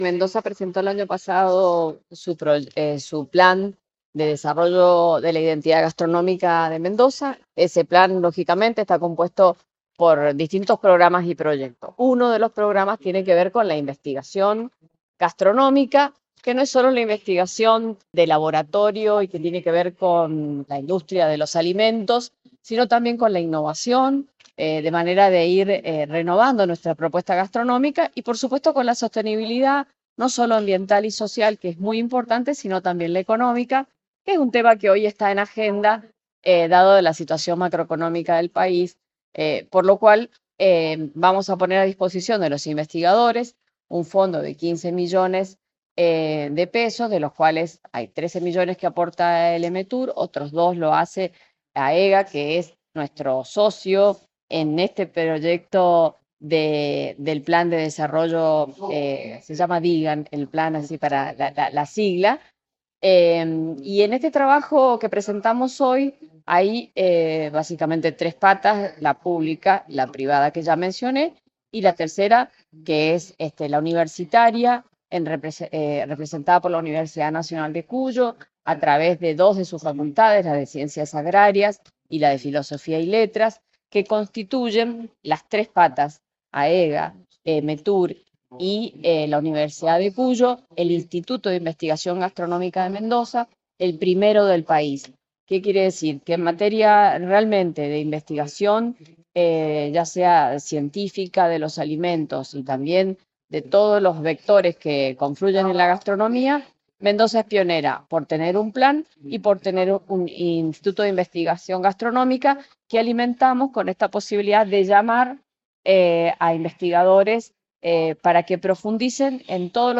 La presentación se realizó este jueves, en el Centro de Congresos y Exposiciones y contó con la presencia de la presidenta del Emetur, Gabriela Testa, funcionarios de la cartera, representantes de AEHGA, la UNCuyo, INTI e INTA y referentes de la gastronomía local.
Gabriela Testa, presidente del EMETUR, sobre Proyectos de investigación en Gastronomía.
Gabriela-Testa-sobre-Proyectos-de-investigacion-en-Gastronomia.mp3